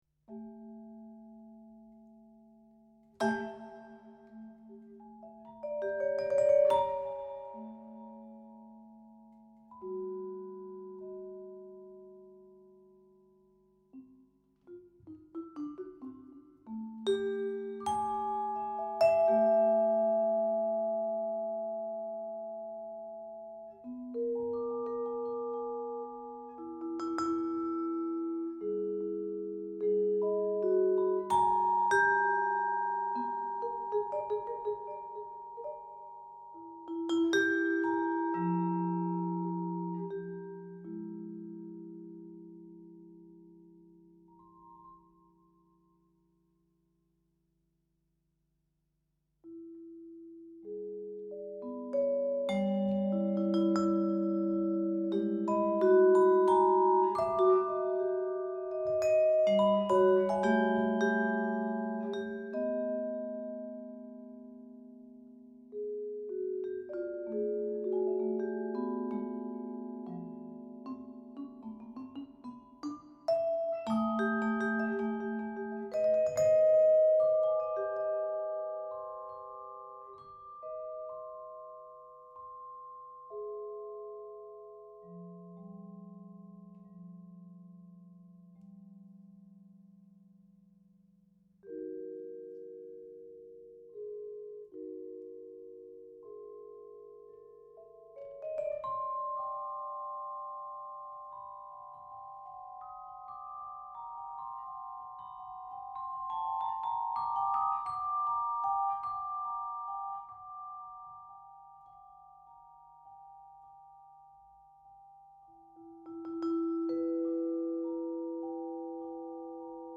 For vibraphone